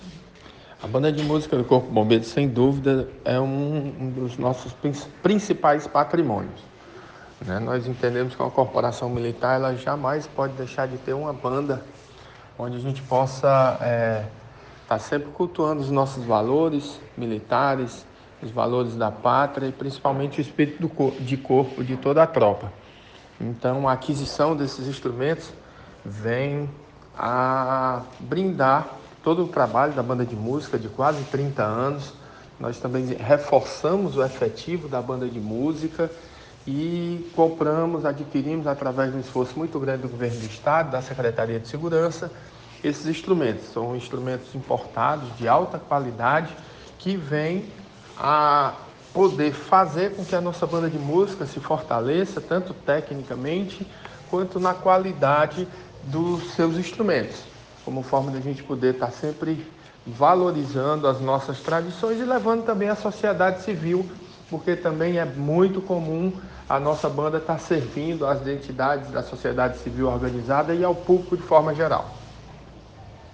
Áudio do Coronel Comandante Geral do CBMCE Luís Eduardo Soares de Holanda